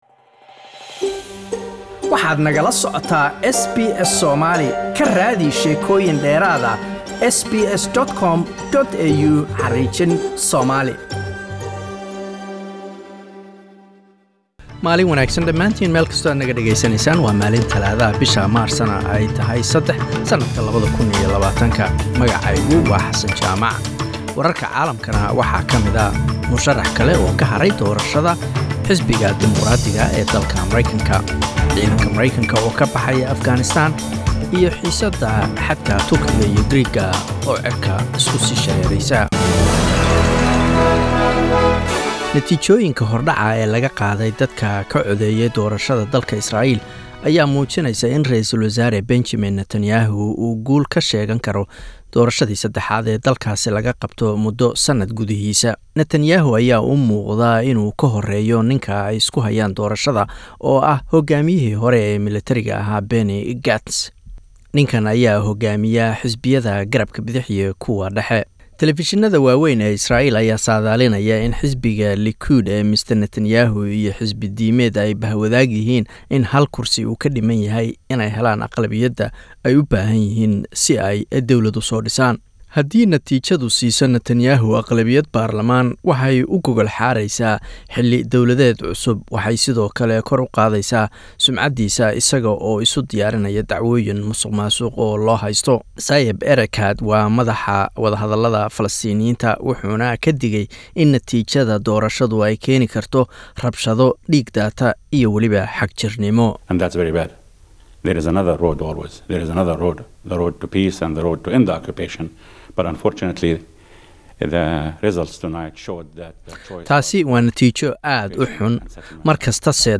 SBS Somali World News Tuesday 03 March